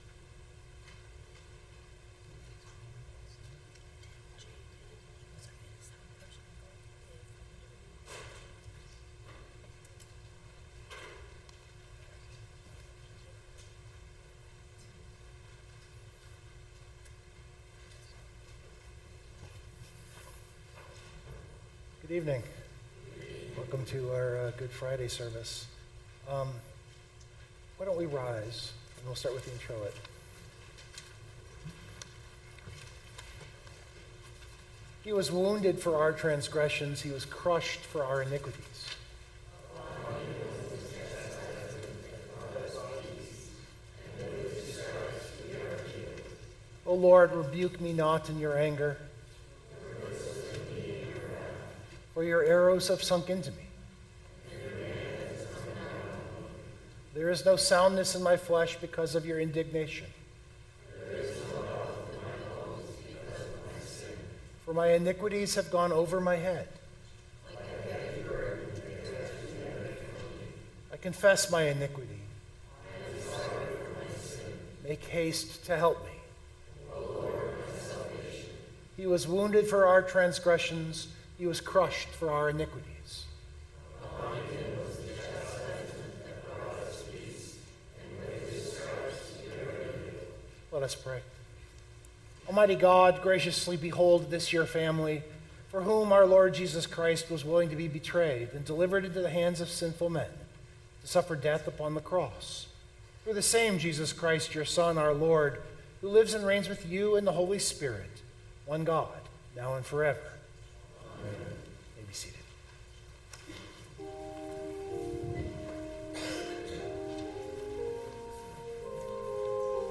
The recording is the full service. The service was a Tenebrae service built around the seven words of Jesus from the cross. The rhythm and theater of the service is Reading, Hymn (which in this case is three verses of a hymn each reading specifically designed for this service), a meditation and the dowsing of a candle (which you can’t see, sorry).